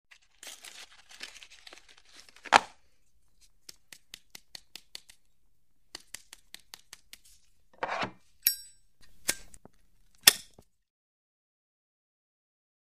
Cigarette From Box, Taps, Lighter Flick And Light